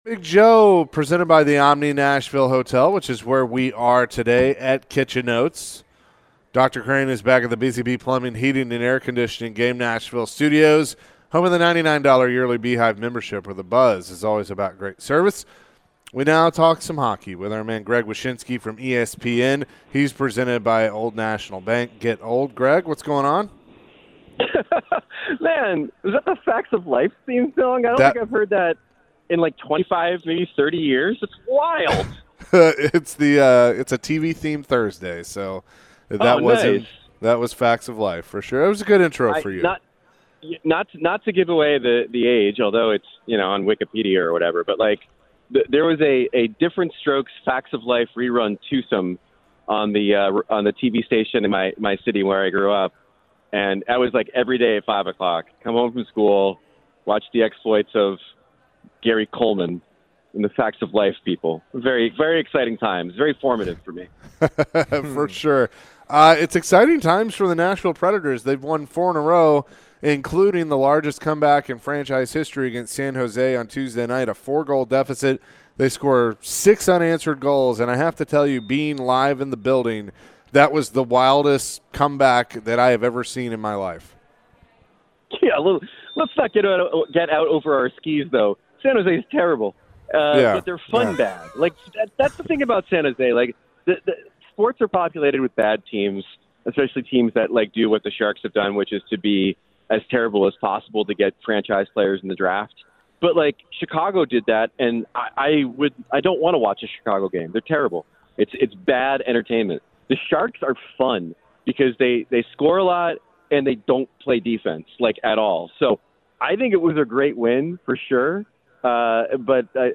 The guys chat hockey with ESPN’s Senior NHL Writer Greg Wyshynski. Wyshynski joined the show and was asked about the NHL and the Preds position in the current standings. What does Greg know about Ryan Johansen’s case with the NHL?